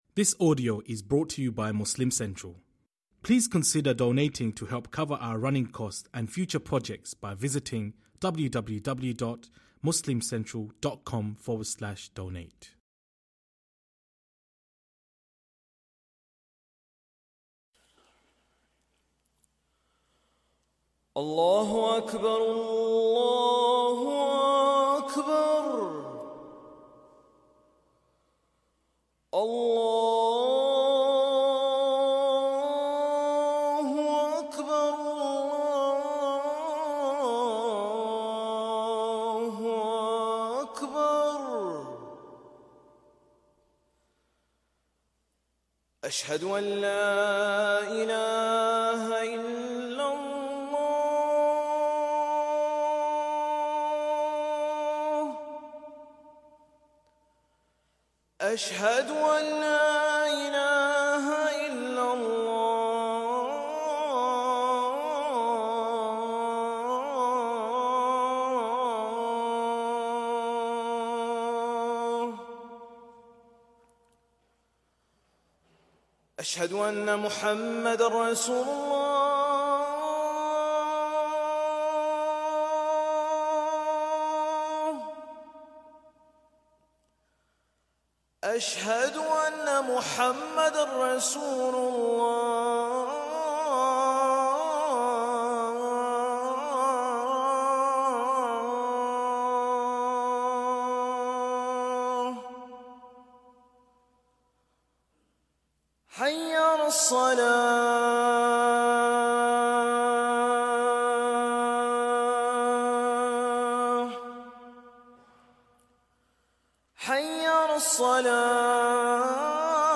Azan / Ezan / Athan / Adhan • Audio Podcast